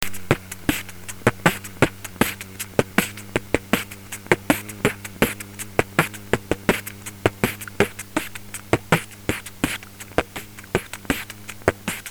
1 файл без коробочки даже хамминг не слышно (мик у меня сейчас полный анекдот)2 файл с коробочкой слышен хамминг и звуки погромче + в конце движение робота моя версия